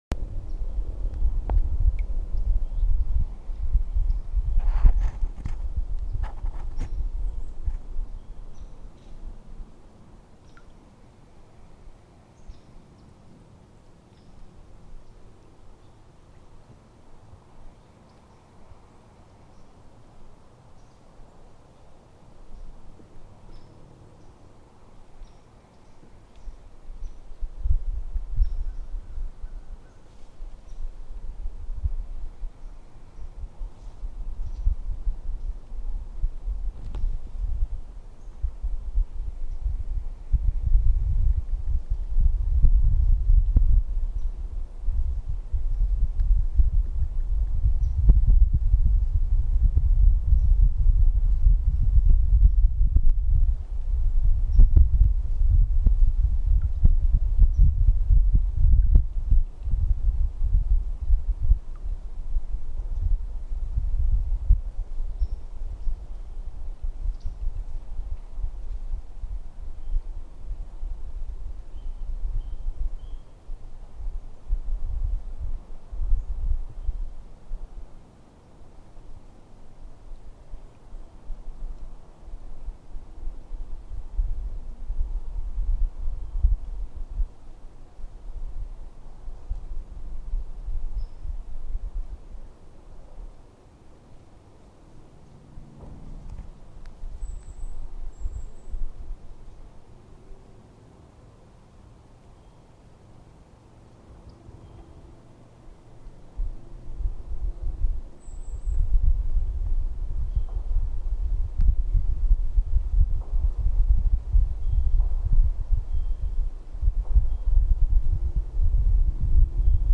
On Lake Dora’s south shore, waterfowl greet paddlers fleeing the din of development. Listen to four minutes and thirty three seconds on the water:
lake-chapman-march-morning-1.mp3